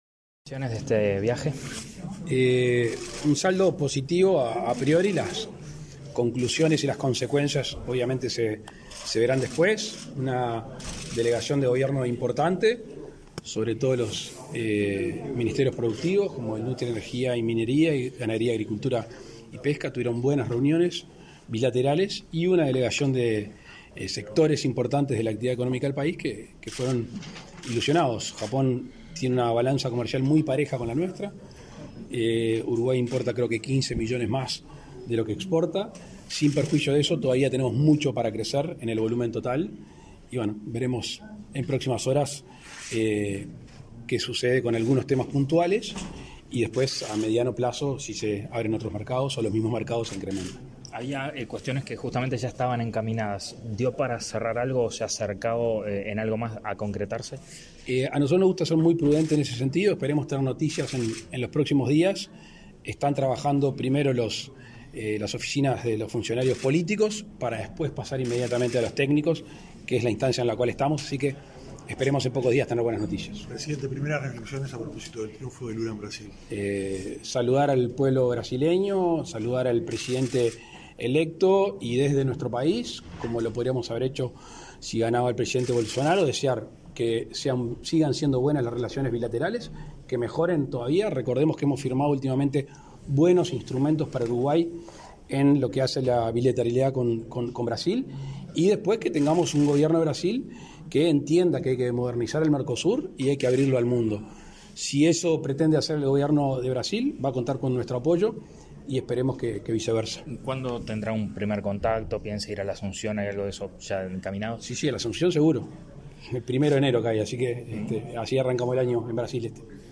Declaraciones a la prensa del presidente de la República, Luis Lacalle Pou
Tras regresar de su viaje oficial a Japón, este 30 de octubre, el presidente de la República, Luis Lacalle Pou, realizó declaraciones a la prensa.